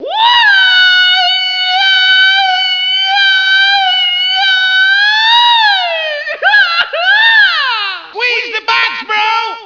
grito.wav